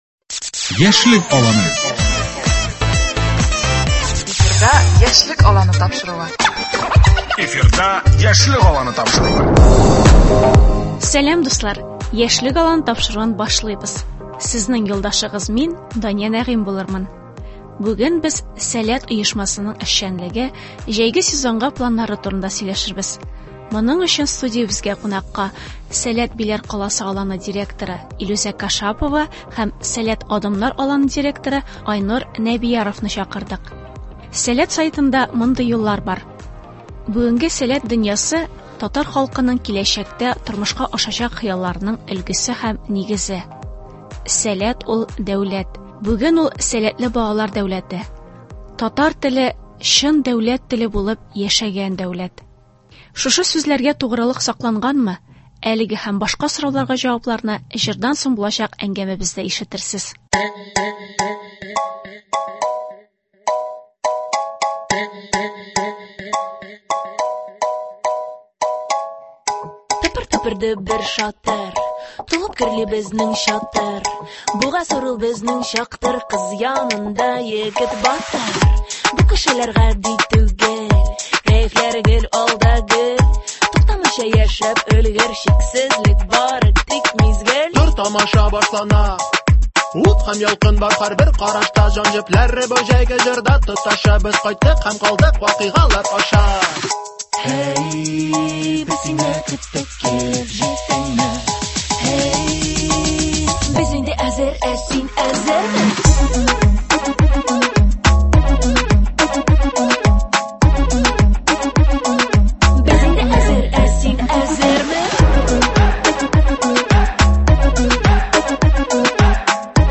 Әлеге һәм башка сорауларга җавапларны җырдан соң булачак әңгәмәбездә ишетерсез.